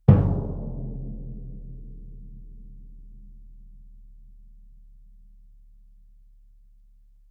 Orchestral Bass
bdrum3_fff_rr1.mp3